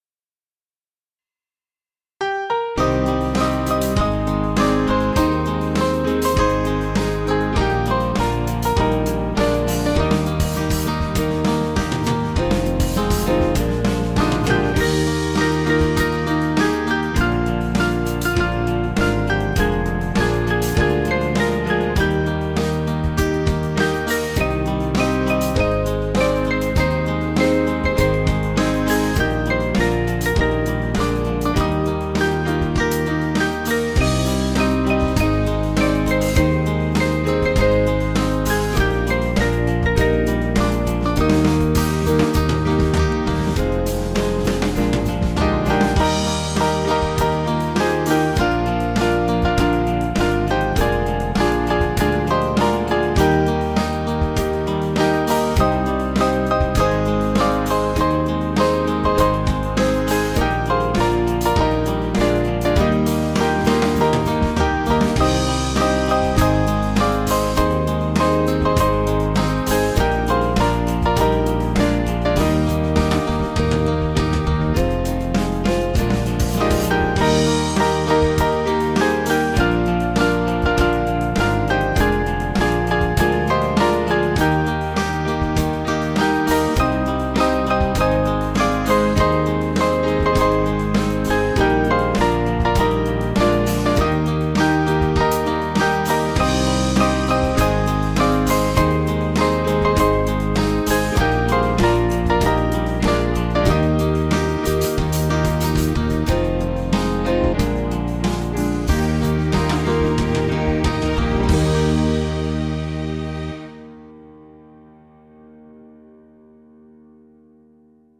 Small Band
(CM)   3/Eb 479.6kb